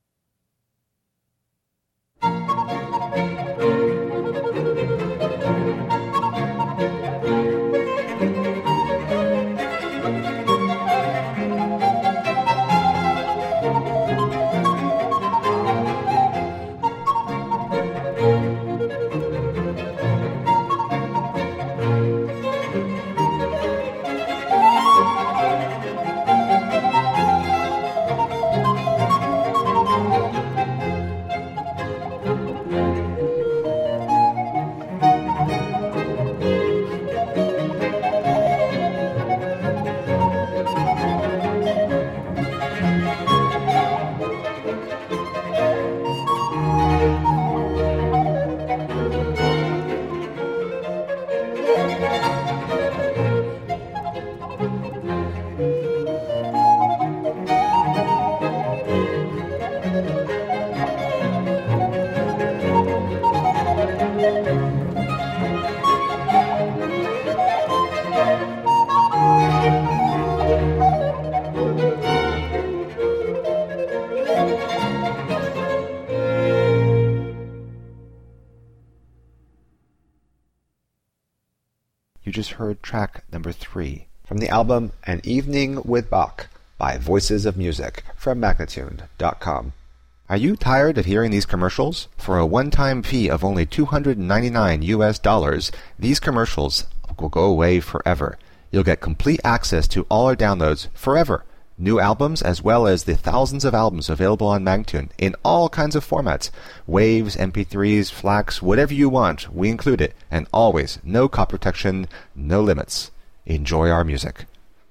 Lilting renaissance & baroque vocal interpretations .